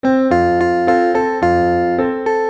Partitura para piano, voz y guitarra.